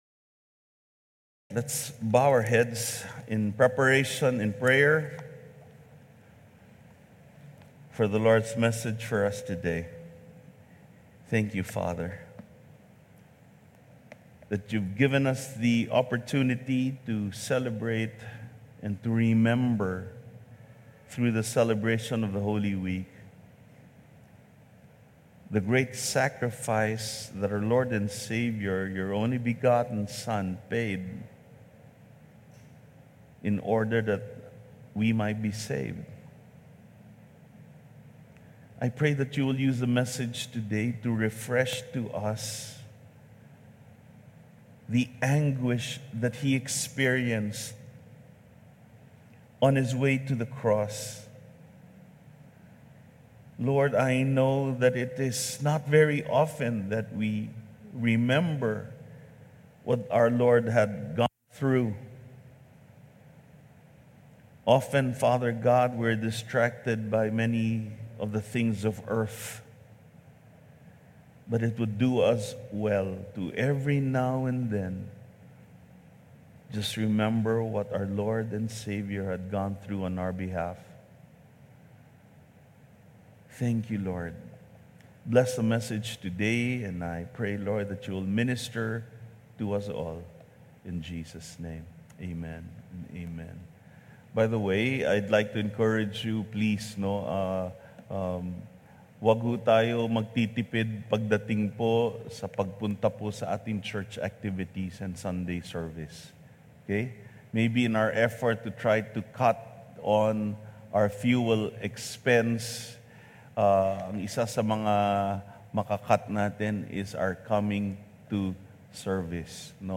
WATCH AND BE BLESSED 2026 GLCC Holy Week Message 1 Videos March 29, 2026 | 9 A.M Service 2026 Holy Week Message: The Anguish of Christ in Gethsemane | Matthew 26:36-39 Information Information Download the Sermon Slides here.